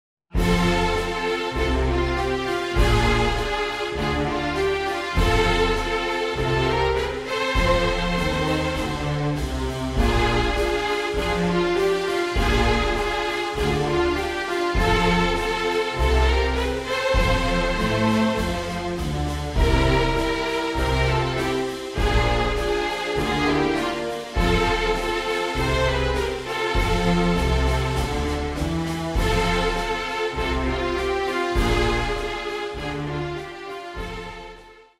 simple twelve bar blues based melodies